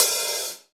OpenHH Turntable 1.wav